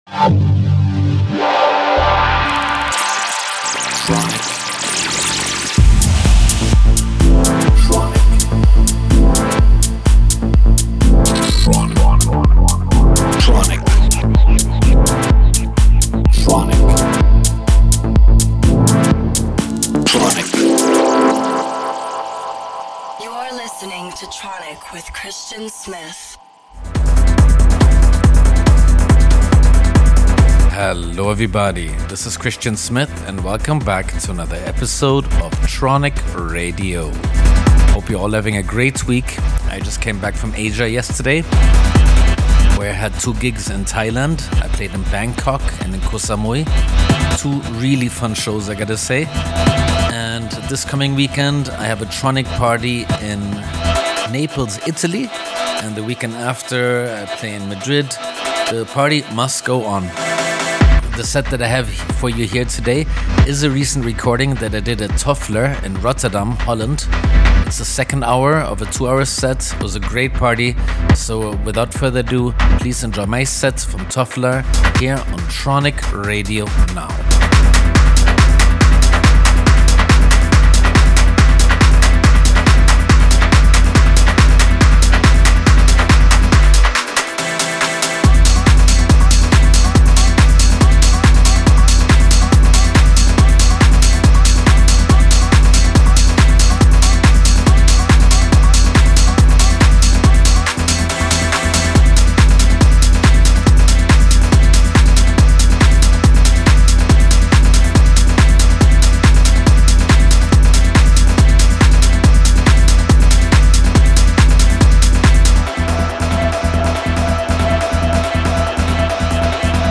Recorded Live